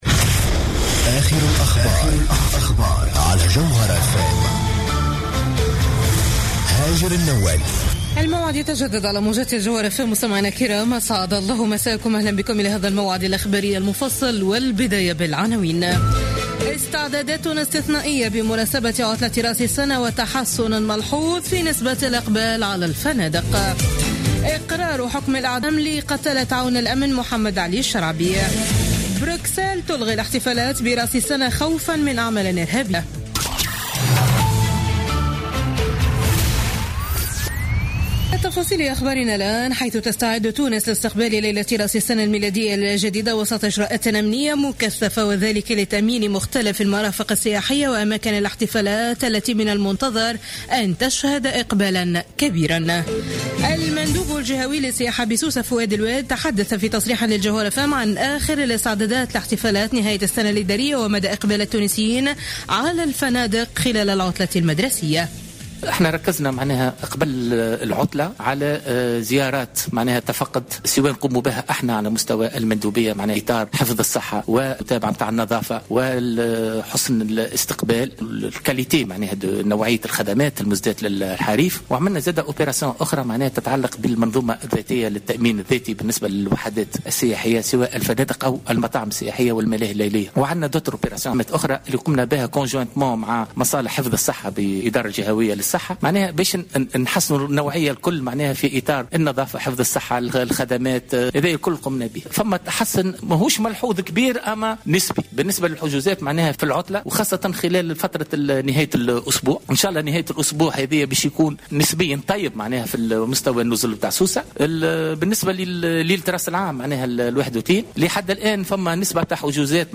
نشرة أخبار منتصف الليل ليوم الخميس 31 ديسمبر 2015